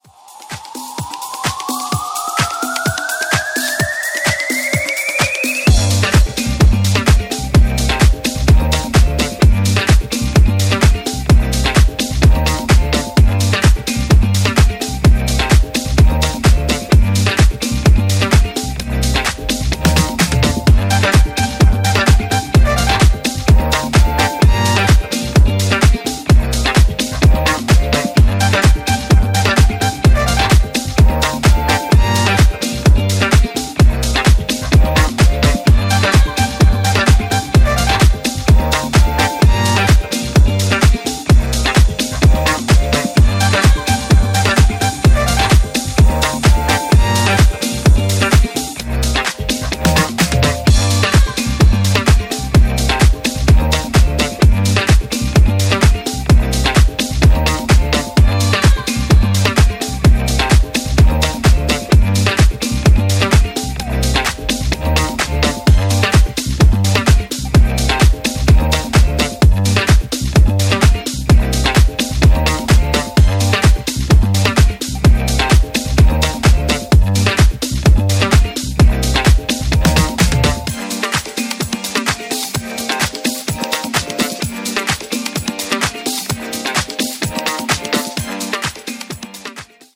ジャンル(スタイル) DISCO / HOUSE / EDITS